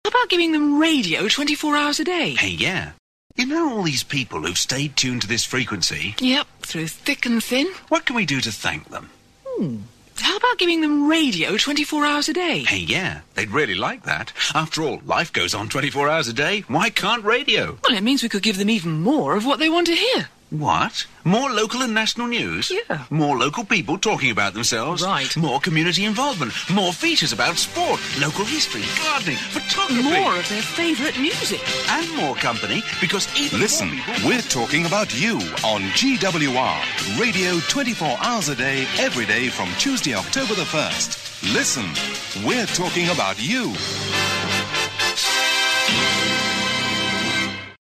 On this launch promo, you can hear the start of the GWR sound.